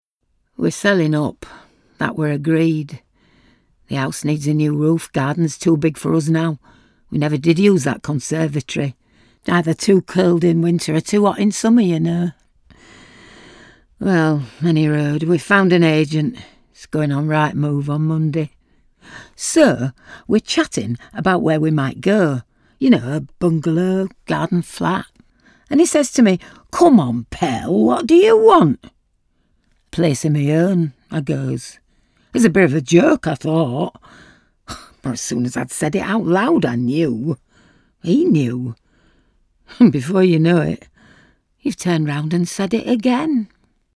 Female
Yorkshire
Confident
Friendly